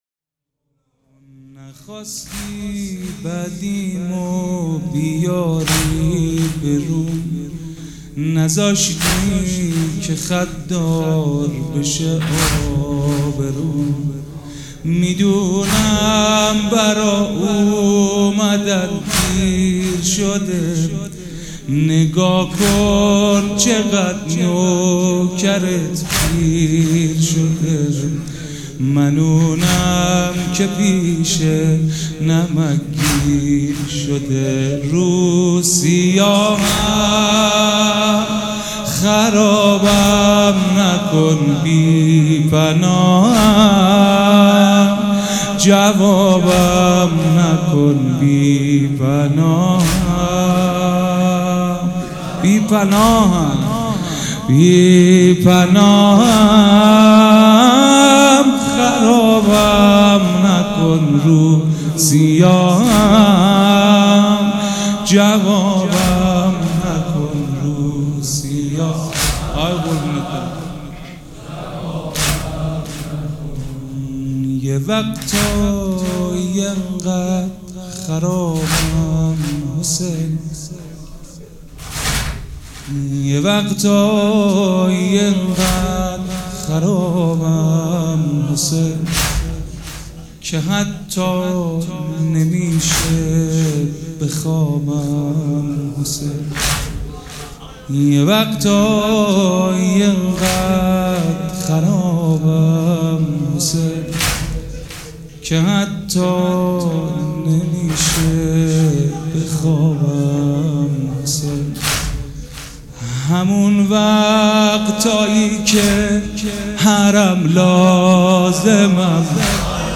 مداح
مراسم عزاداری شب دوم